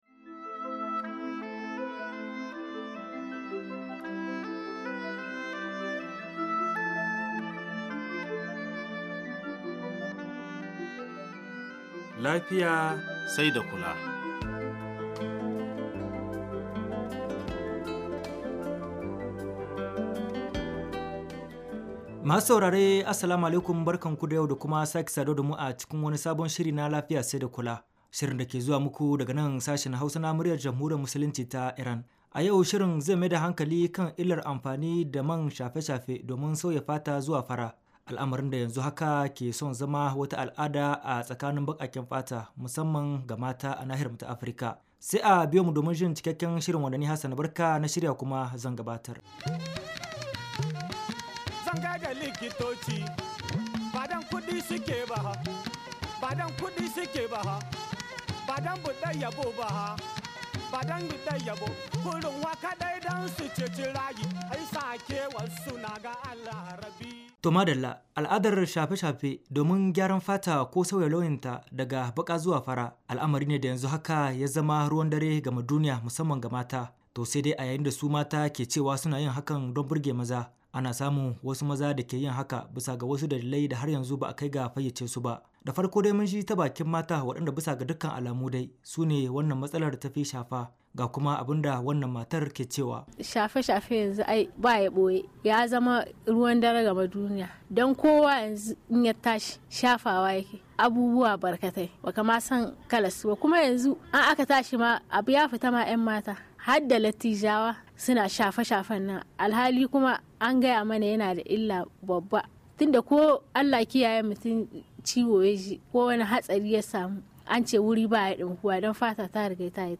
mun ji ta bakin wasu mata